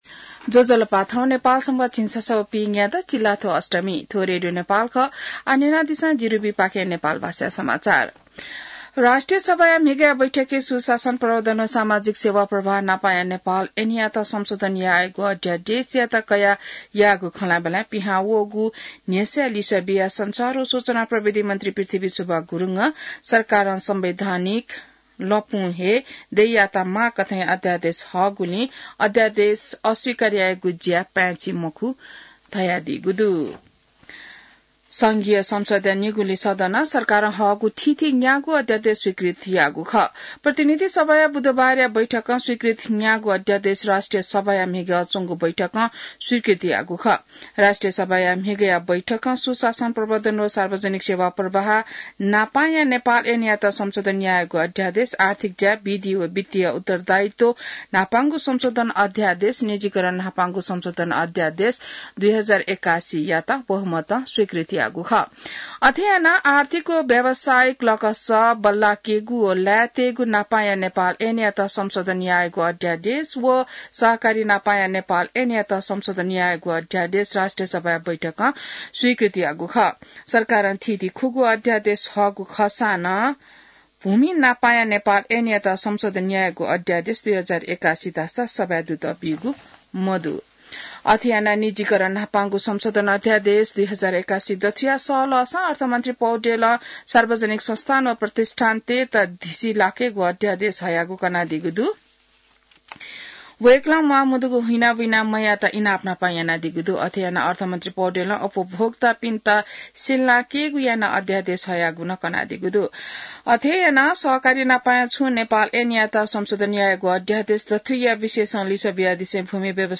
नेपाल भाषामा समाचार : २४ फागुन , २०८१